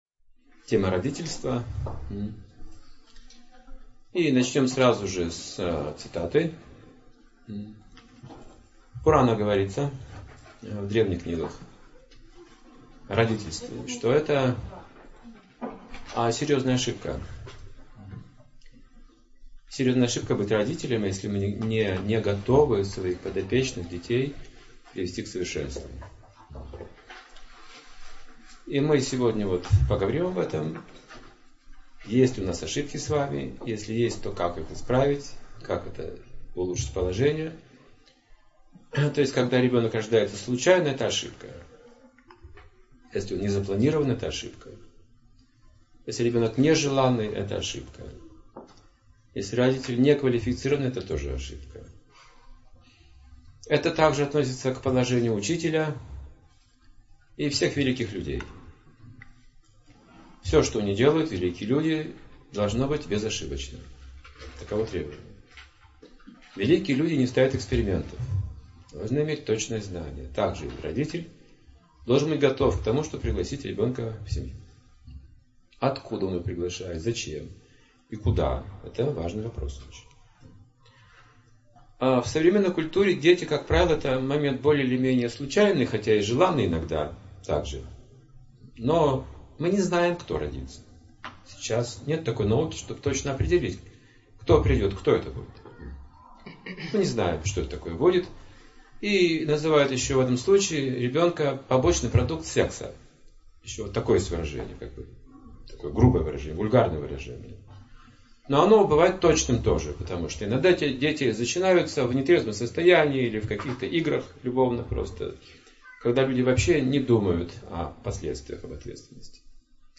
Как сформировать условия для гармоничного развития детей? - ответы на эти и многие другие вопросы мы находим в лекции о воспитании детей.